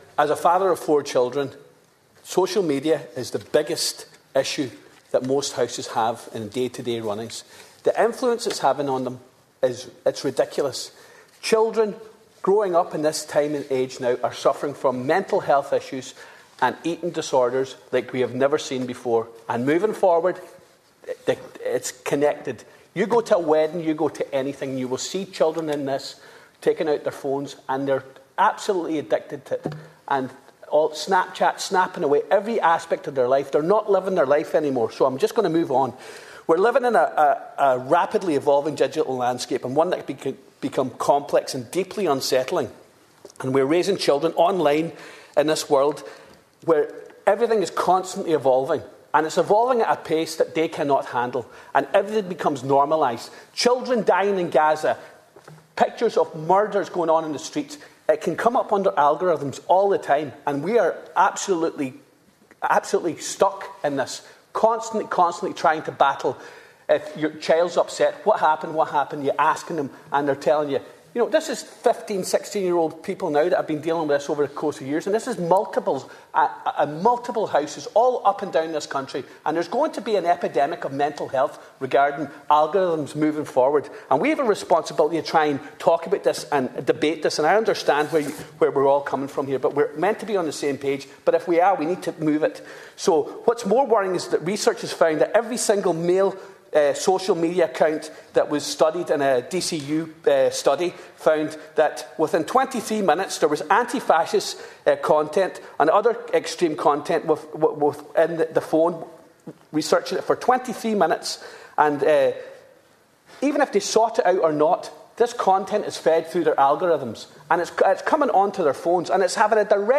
Deputy Charles Ward, himself a father of four, was speaking in the Dail in support of a People Before Profit motion on online safety, which would require social media companies to turn off recommender algorithms for under 18s.